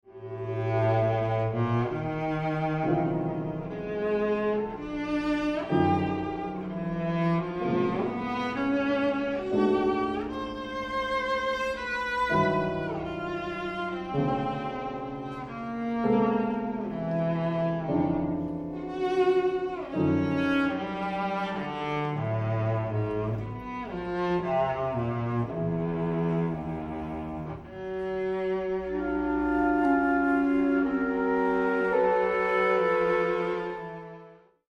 für Flöte, Klarinette, Violoncello u. Klavier (1991)